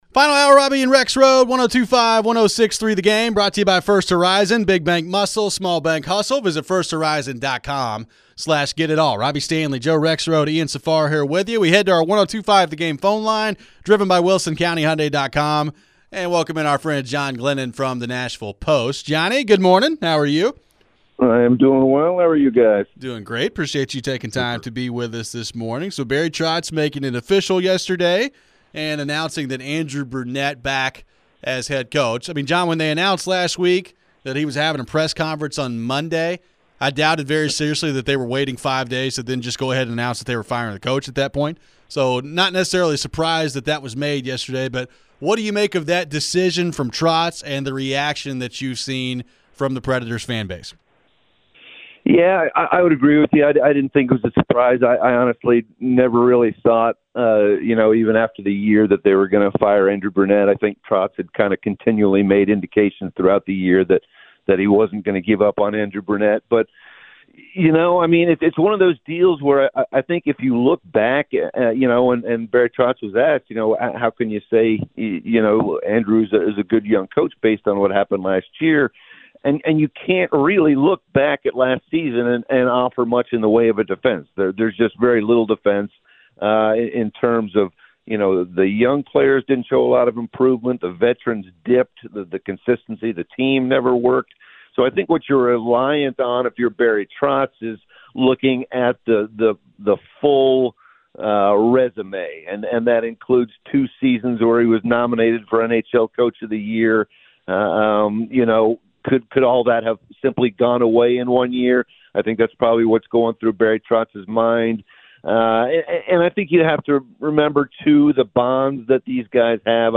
We get back into the phones on the Preds, Brunette returning, and the draft lottery. Can they find a good player at pick #5?